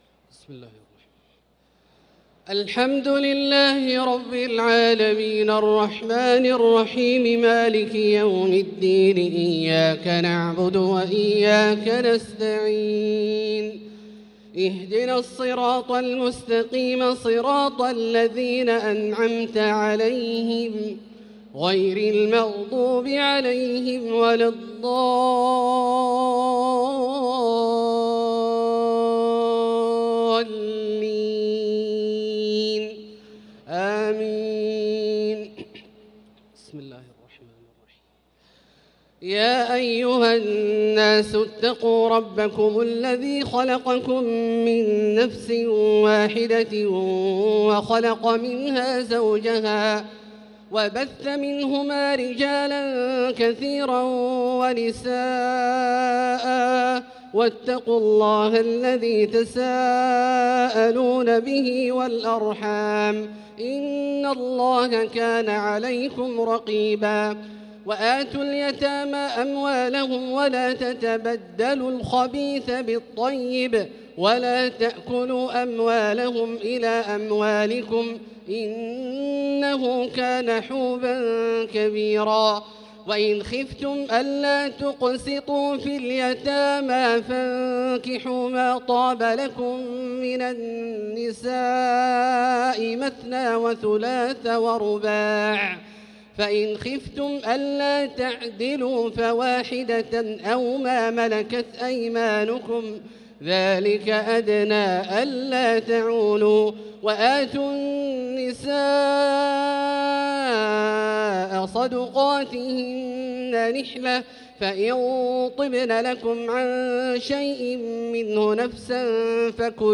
صلاة التراويح ليلة 6 رمضان 1445 للقارئ عبدالله الجهني - الثلاث التسليمات الأولى صلاة التراويح